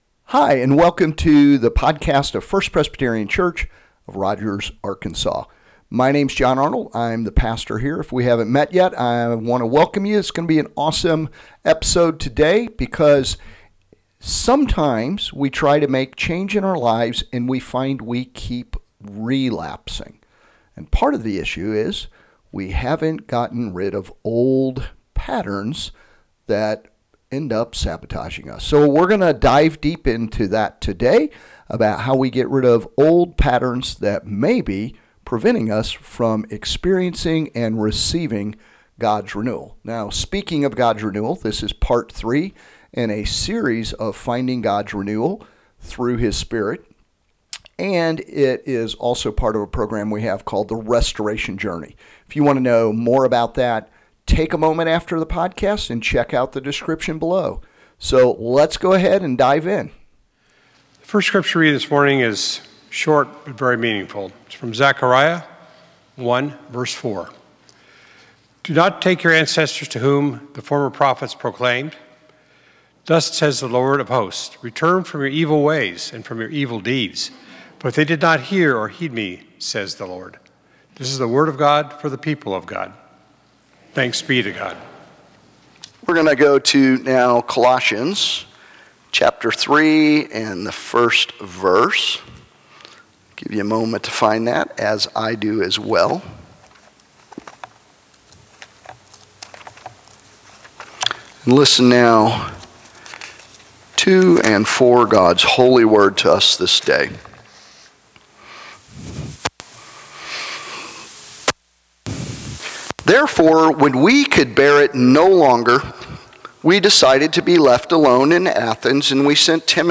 ABOUT THE RESTORATION JOURNEY This sermon is part of a six-part series on finding renewal by God’s Spirit and program called The Restoration Journey.